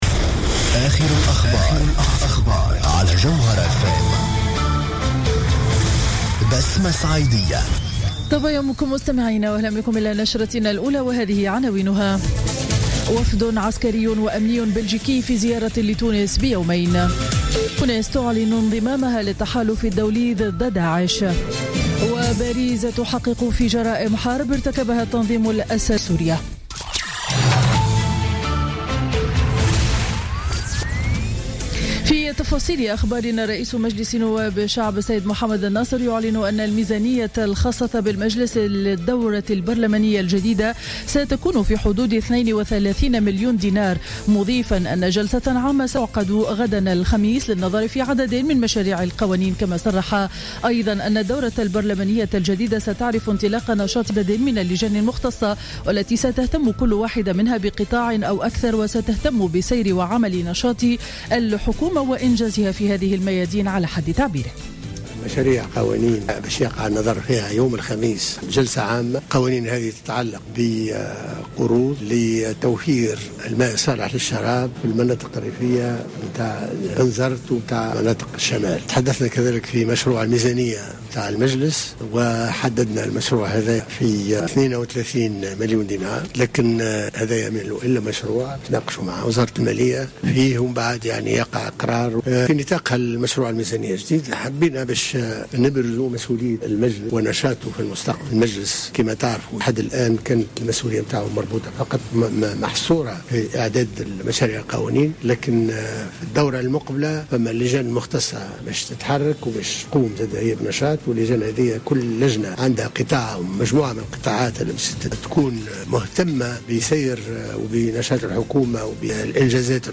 نشرة أخبار السابعة صباحا ليوم الأربعاء 30 سبتمبر 2015